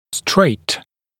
[streɪt][стрэйт]примой; прямо, по прямой линии